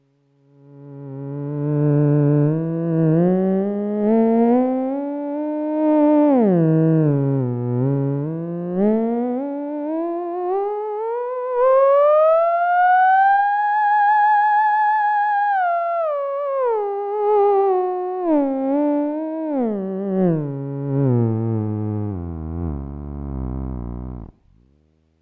It shows great potential out of the box but I do lose some of my dual tube fat sound but retain the audio harmonic dance that an audio spectrum analyzer will reveal.
It is a clean sound; not a cheap whistle buried in reverb so this could evolve into something more musical. Some how there is a swoosh effect in the sound when rapidly moving from note to note, needs more study.
altermen-crystal2.mp3